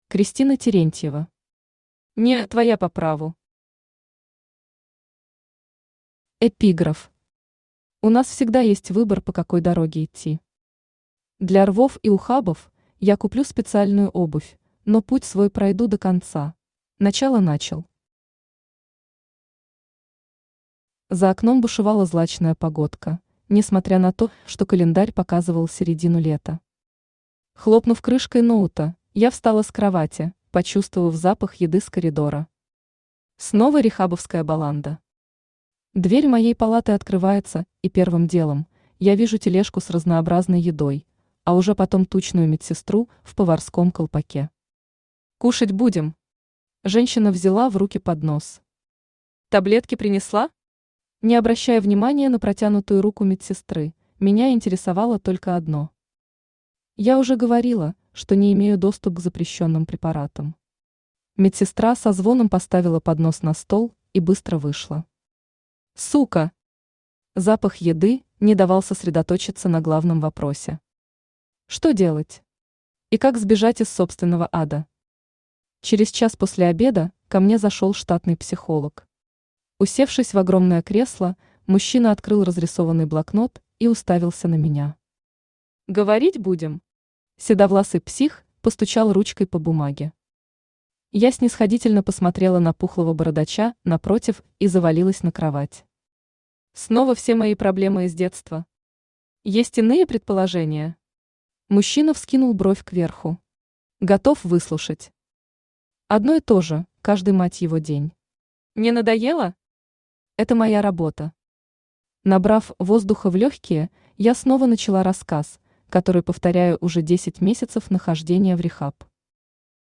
Аудиокнига (Не)твоя по праву!
Автор Кристина Терентьева Читает аудиокнигу Авточтец ЛитРес.